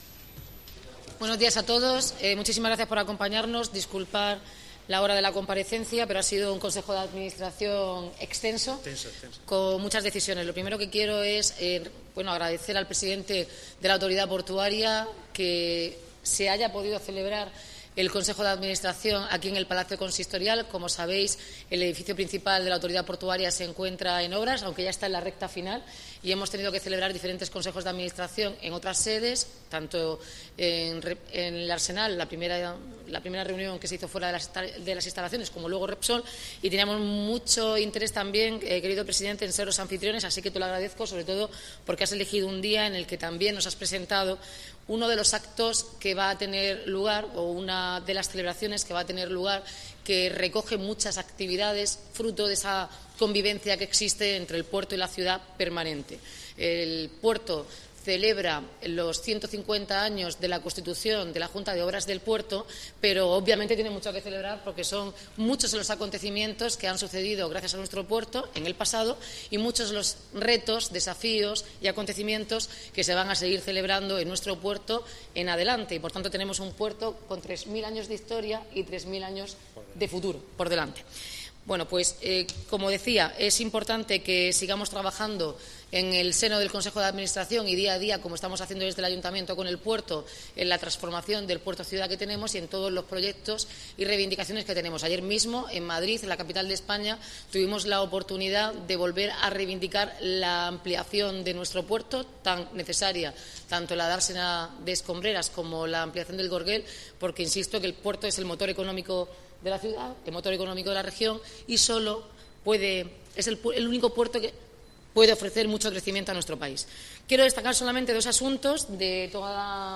Enlace a Declaraciones de la alcaldesa, Noelia Arroyo, y el presidente de la Autoridad Portuaria, Pedro Pablo Hernández